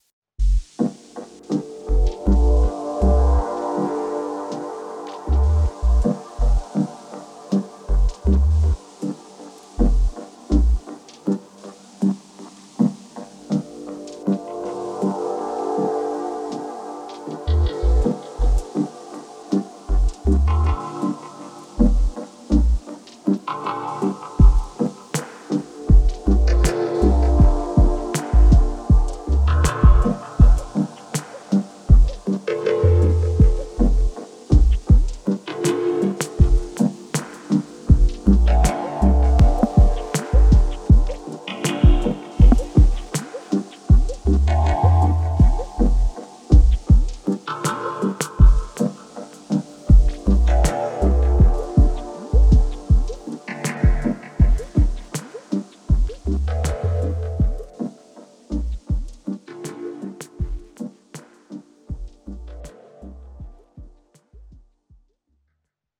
Since we’re all sharing here are some dubby demos I did about a year ago.